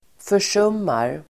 Uttal: [för_s'um:ar]